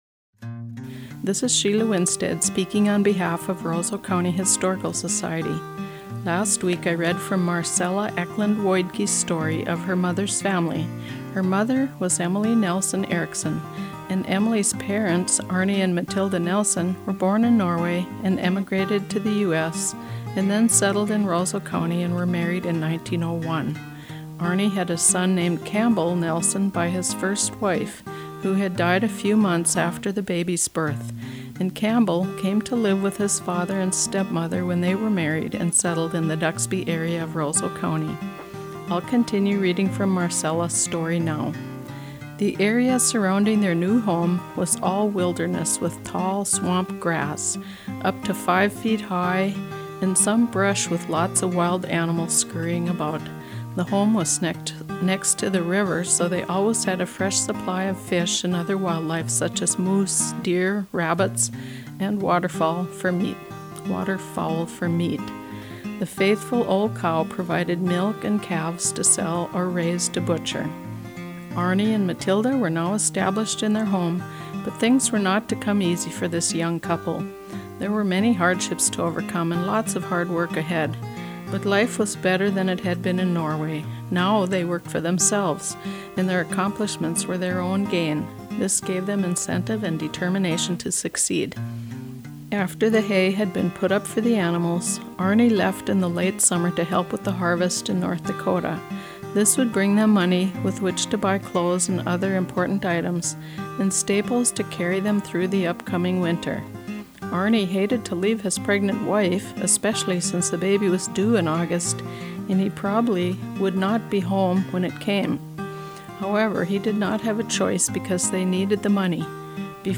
Listen to the Weekly Radio Readings